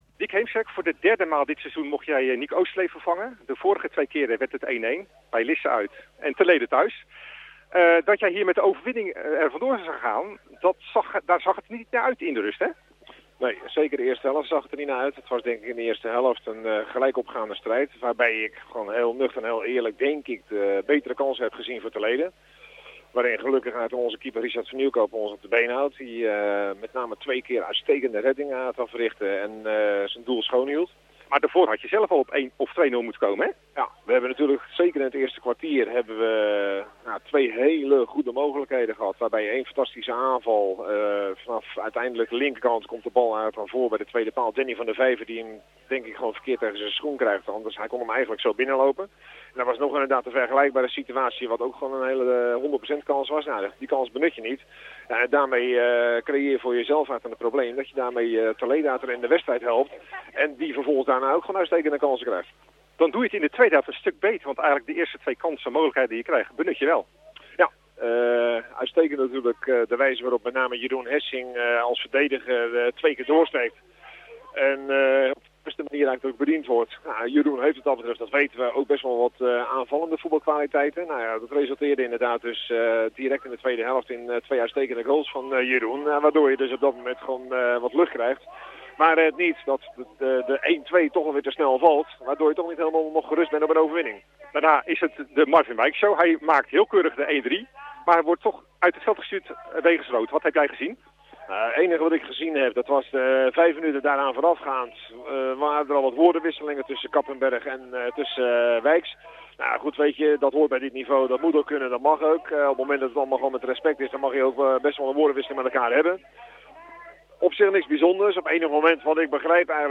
Nabeschouwing